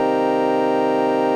En l'occurrence, j'ai créé des samples stereo que je n'arrive pas à lire.
Voici un fichier (un accord joué à l'orgue) qui n'est pas reconnu :